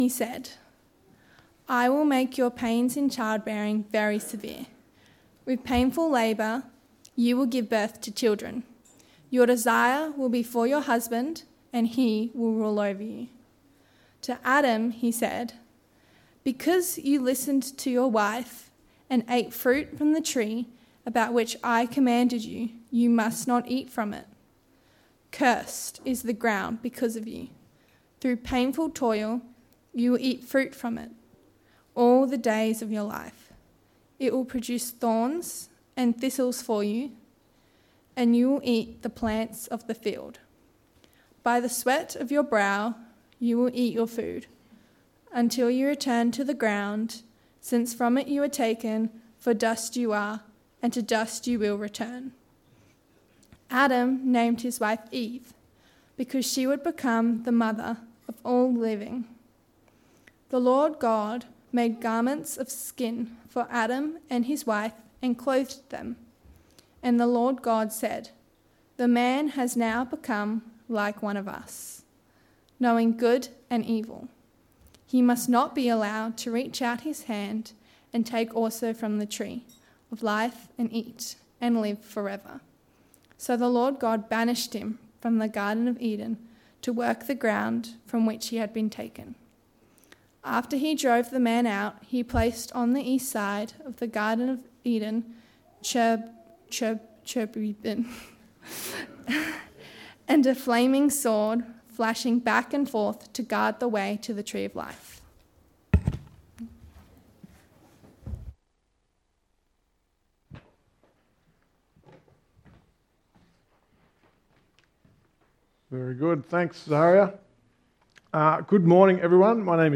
Text: Genesis 3: 16-24 Sermon